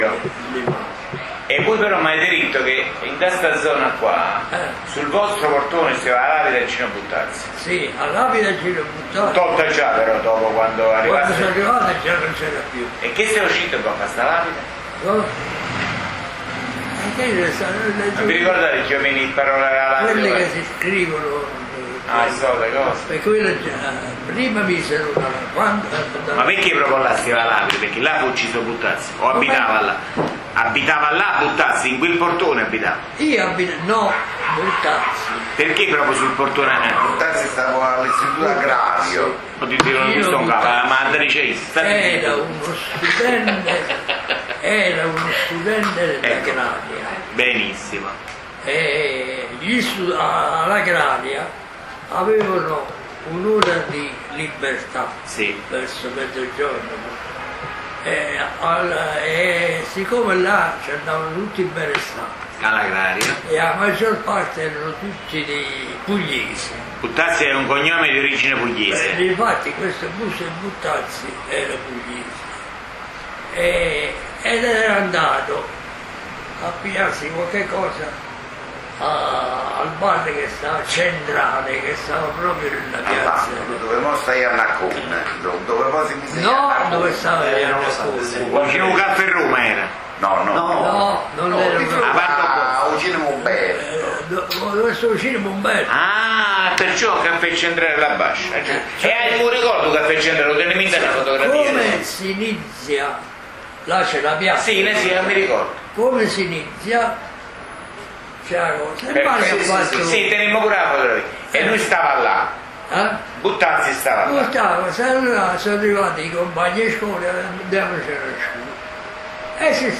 Conversazione con un avellinese doc